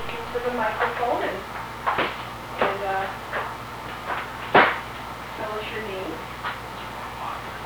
EVPs
This clip has been normalized using Cool Edit Pro